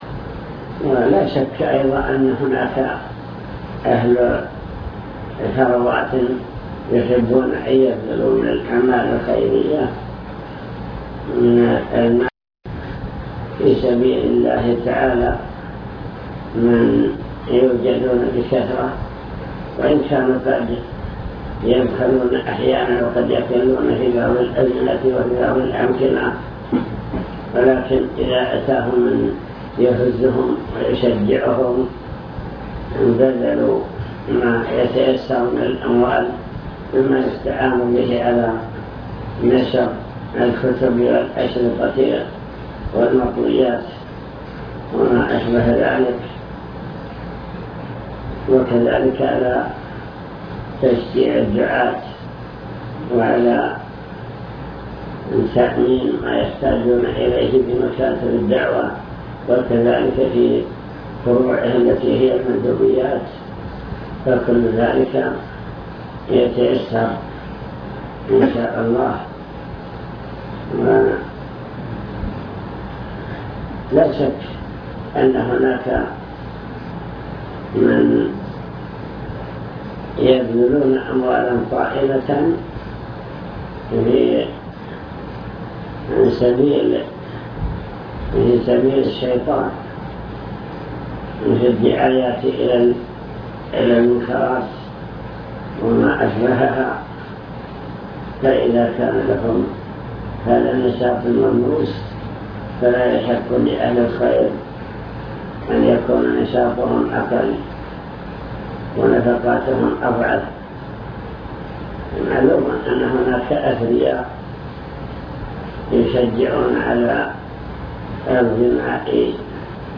المكتبة الصوتية  تسجيلات - لقاءات  كلمة توجيهية في مكتب الدعوة بجدة